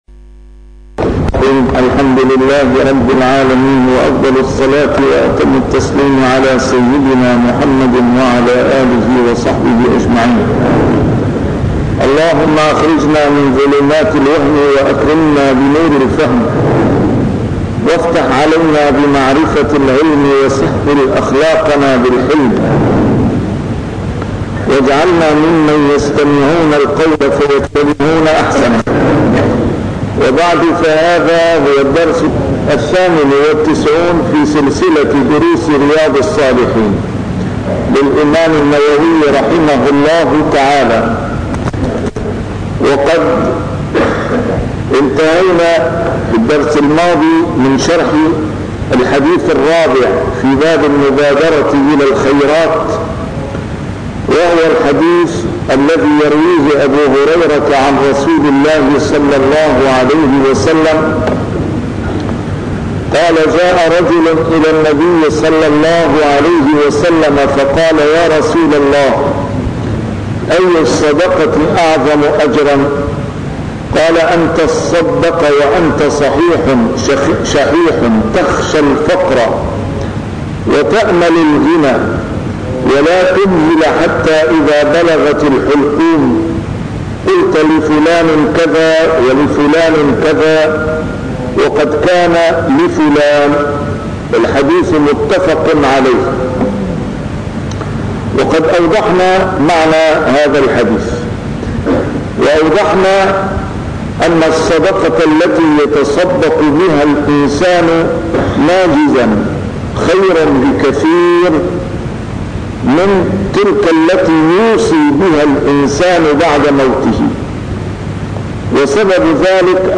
A MARTYR SCHOLAR: IMAM MUHAMMAD SAEED RAMADAN AL-BOUTI - الدروس العلمية - شرح كتاب رياض الصالحين - 98- شرح رياض الصالحين: المبادرة إلى الخيرات